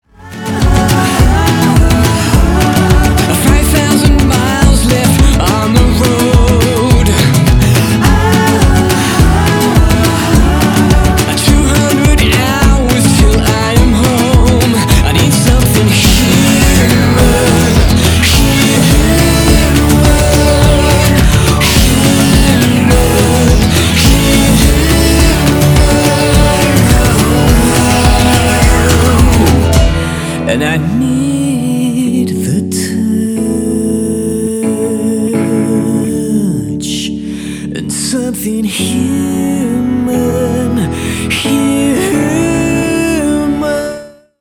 • Качество: 320, Stereo
alternative
indie rock
приятный мужской голос